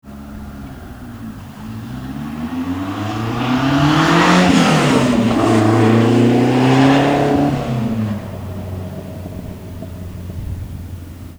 MP3 SOUND CLIPS Old Flowmaster - S4s Chip New Magnaflow - Hi-Torque Chip plus BOV
Flowmaster_1st_2nd.mp3